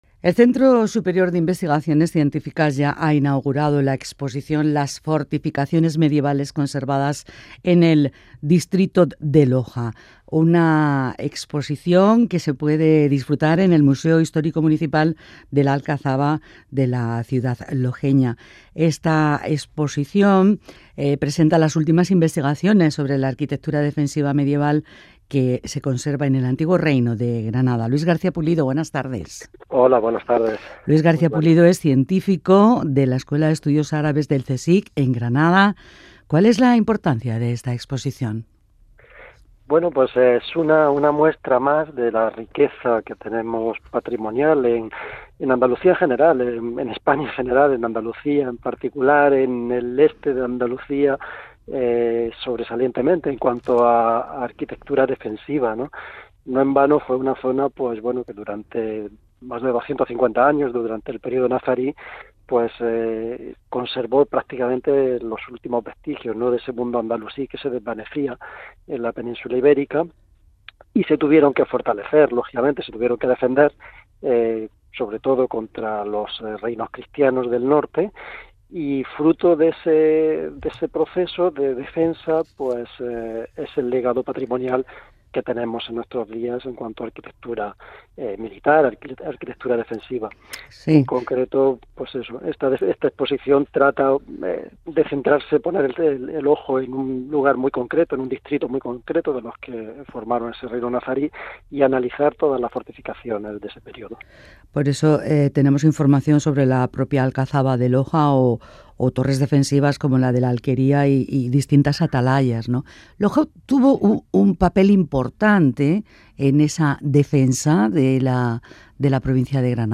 El Museo Histórico de la Alcazaba acoge una exposición sobre las fortificaciones medievales del distrito de Loja Canal Sur Radio . Entrevista